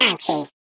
File:Sfx tool spypenguin vo hit wall 03.ogg - Subnautica Wiki